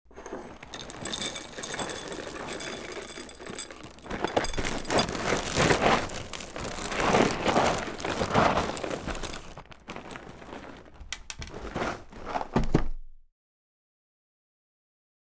Cereal Box Pouring into Bowl Sound
household